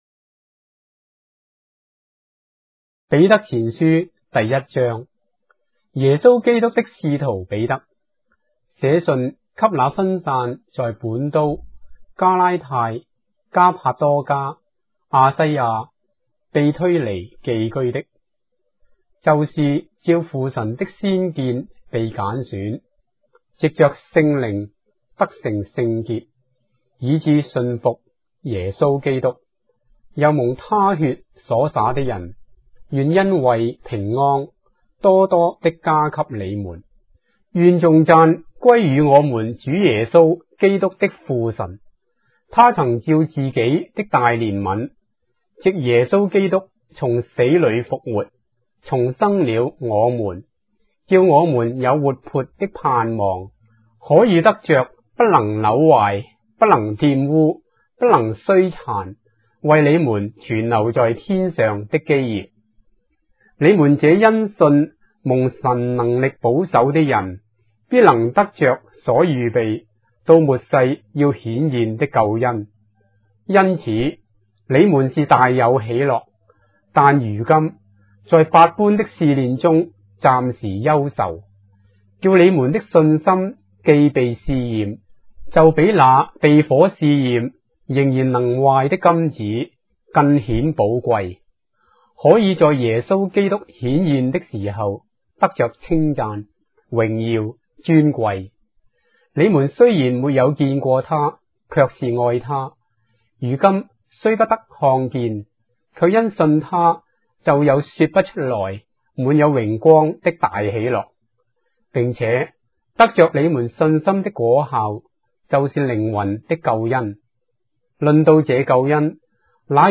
章的聖經在中國的語言，音頻旁白- 1 Peter, chapter 1 of the Holy Bible in Traditional Chinese